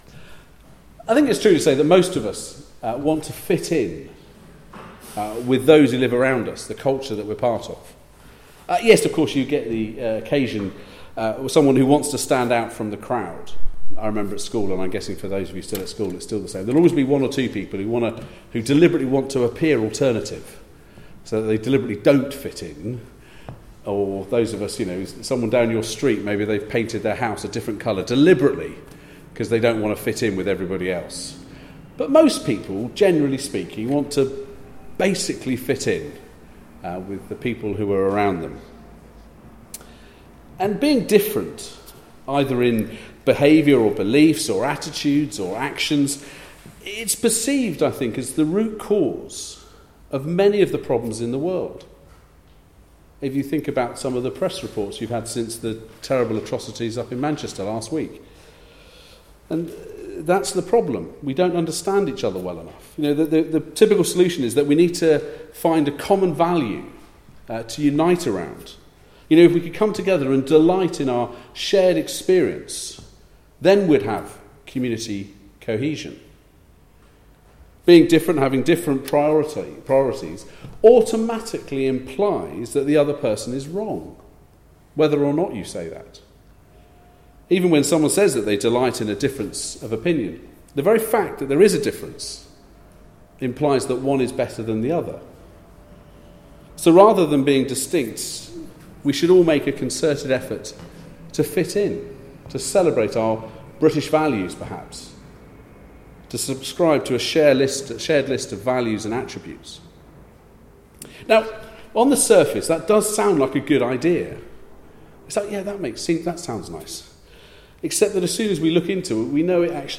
Passage: 1 Samuel: 8:1-9:27 Service Type: Weekly Service at 4pm